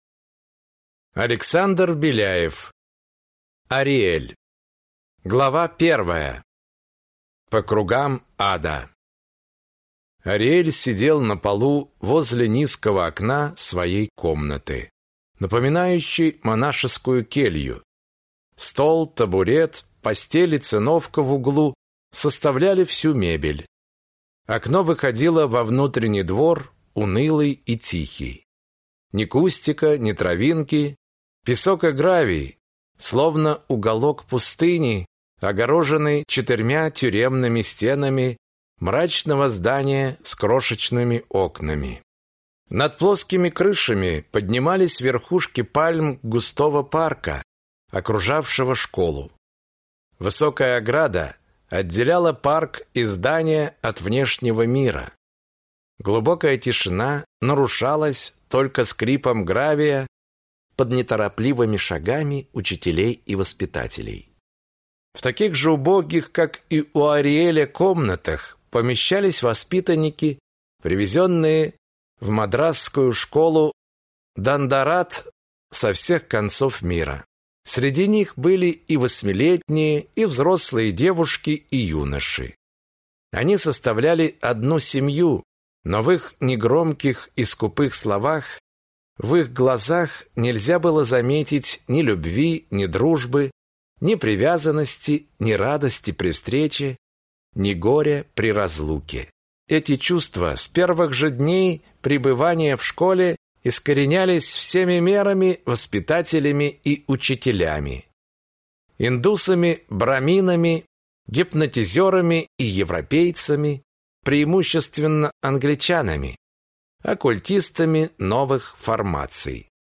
Аудиокнига Ариэль | Библиотека аудиокниг